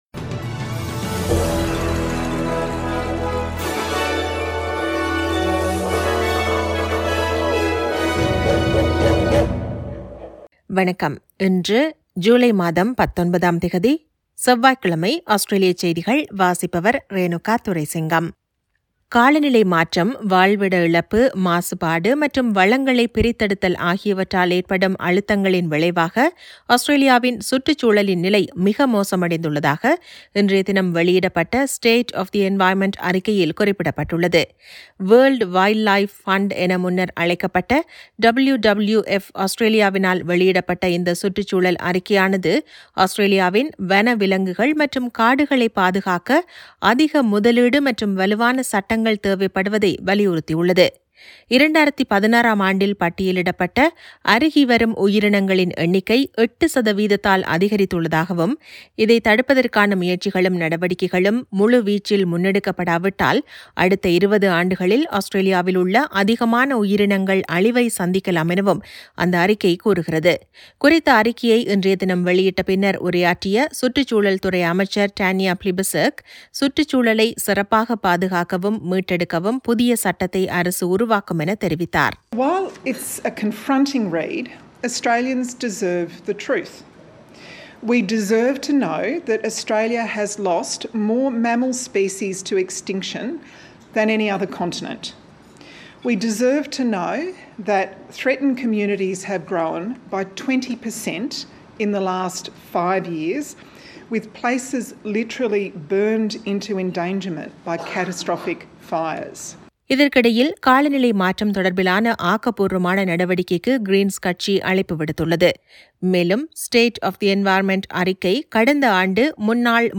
Australian news bulletin for Tuesday 19 July 2022.